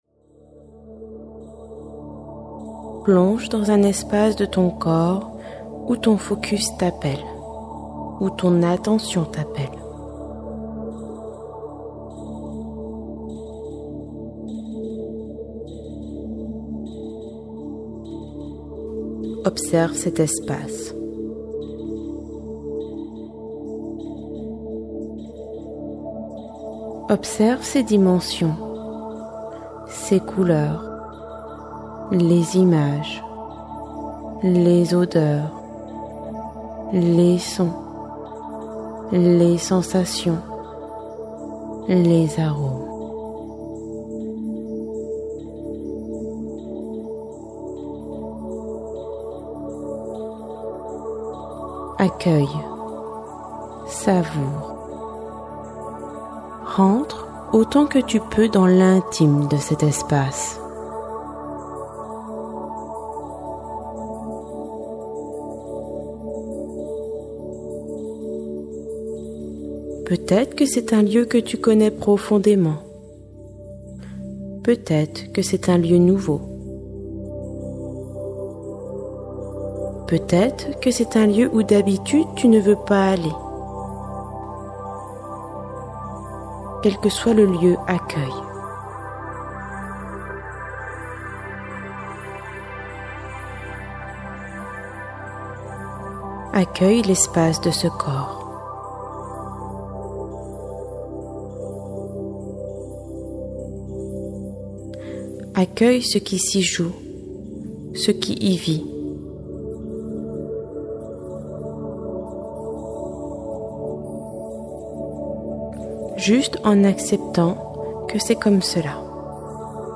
Cinq séances de méditation-guidance pour se réconcilier